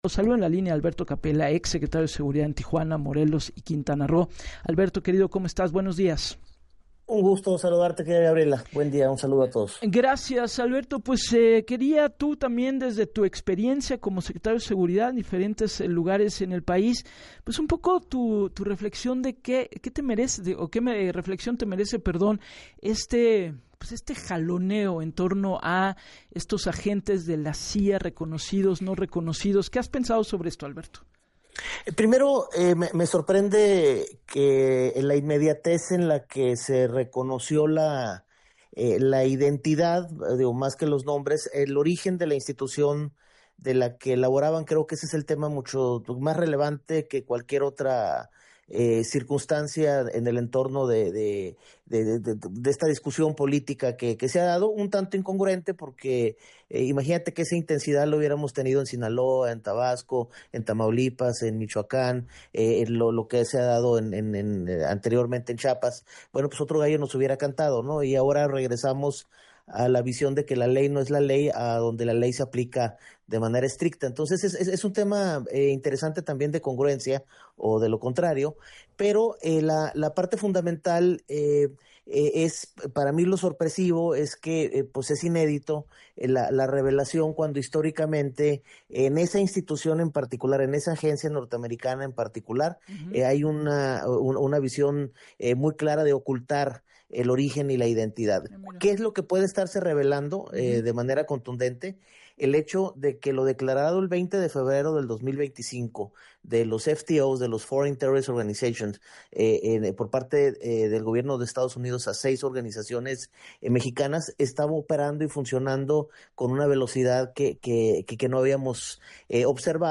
El exsecretretario de seguridad recordó en entrevista para “Así las Cosas” con Gabriela Warkentin, que desde febrero de 2025 con la declaración de Estados Unidos contra las Organizaciones Terroristas Extranjeras mexicanas, están utilizando todos los instrumentos de acceso institucional particularmente entre las entidades fronterizas para tener este nivel de colaboración.